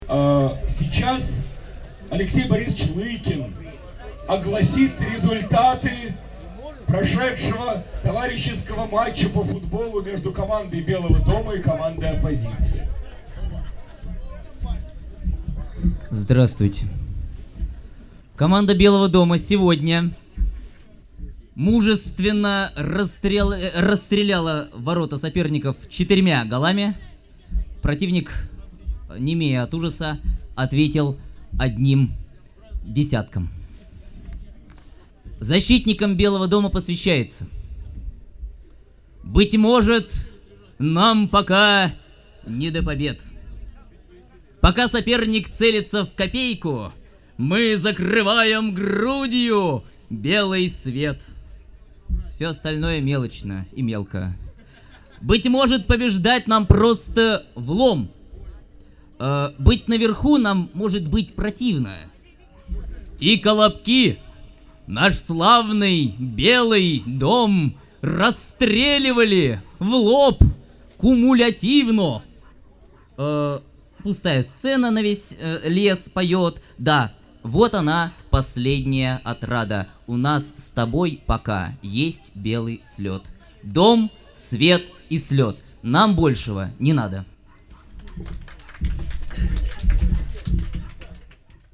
Запись концерта БС2002